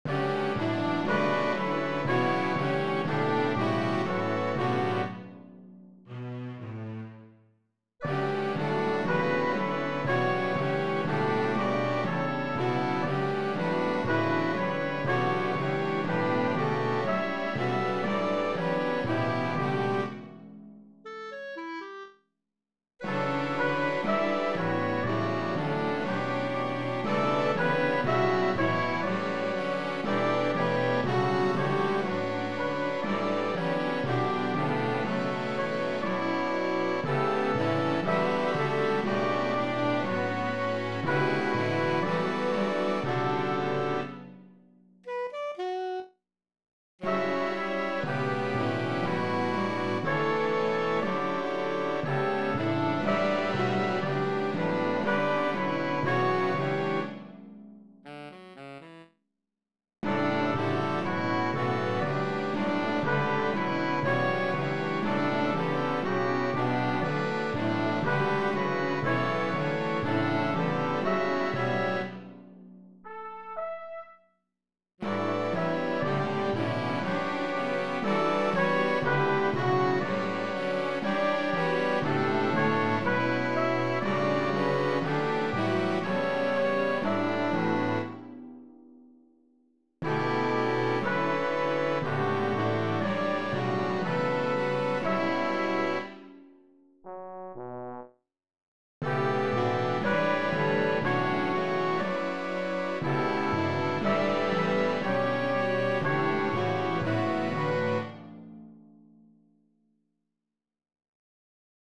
From my Third most recent original musical composition Symphony; Duisburg Somer.